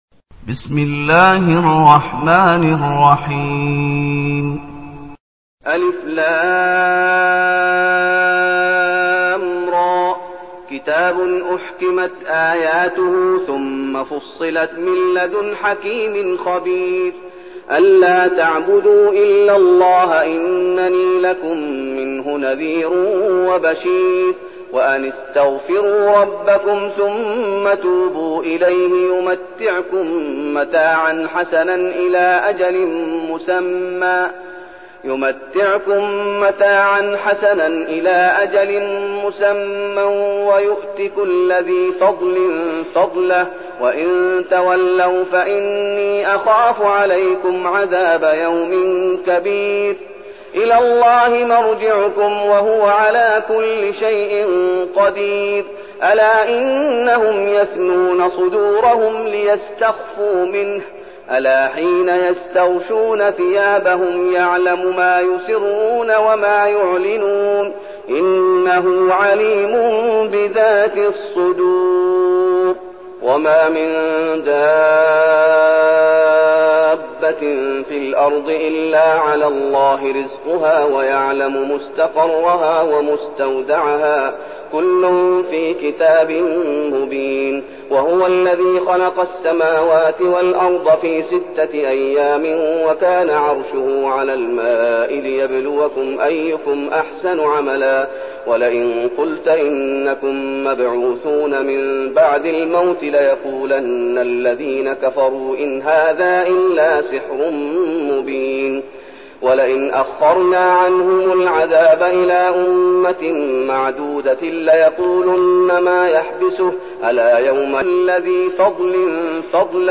المكان: المسجد النبوي الشيخ: فضيلة الشيخ محمد أيوب فضيلة الشيخ محمد أيوب هود